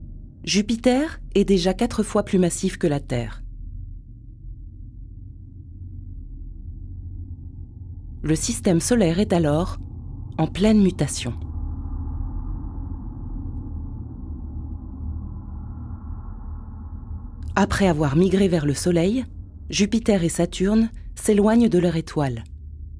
Enregistrements qualité studio
Voix narrative
Naturelle et immersive, conçue pour le documentaire narratif
documentaire.wav